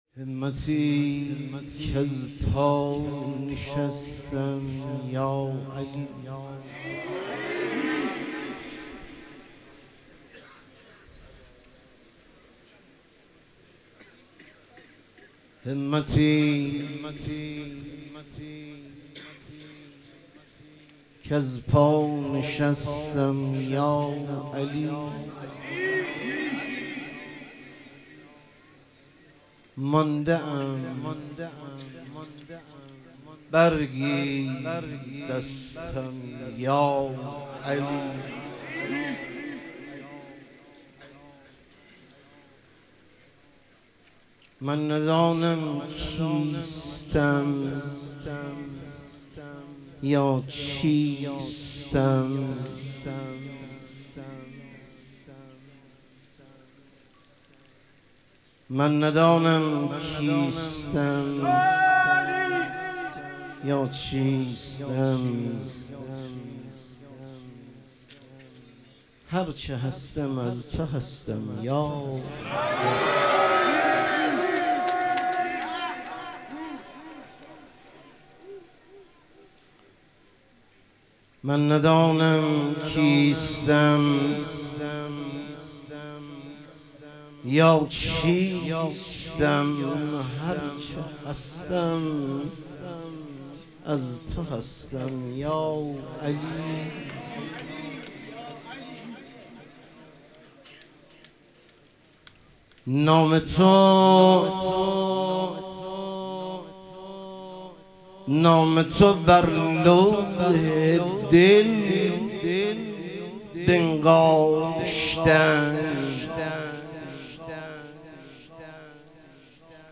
ظهور وجود مقدس حضرت امیرالمومنین علیه السلام - مدح و رجز